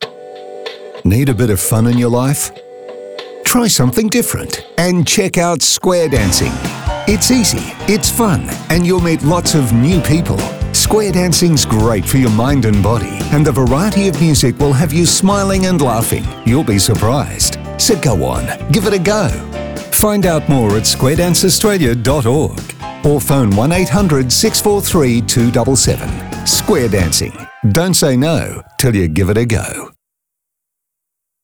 Promotional Audio Downloads